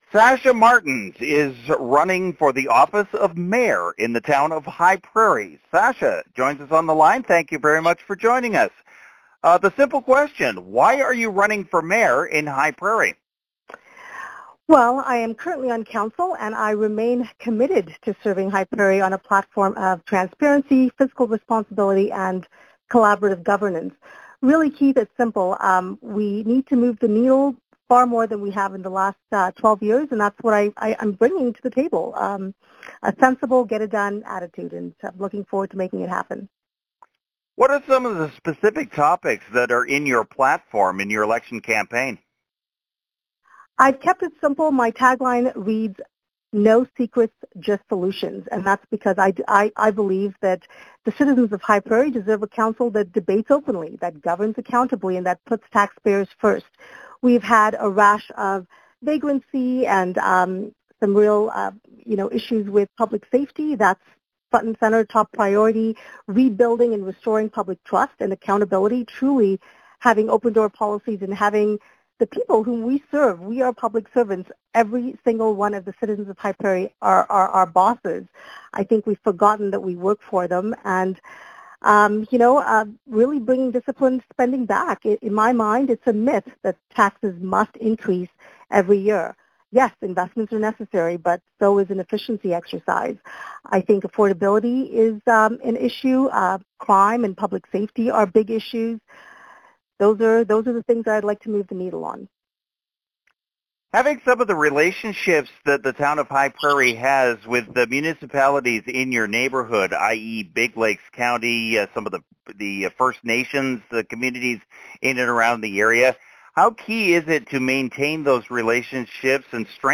Trending 55 is talking with the Mayor candidates in the region for next week’s Municipal Election.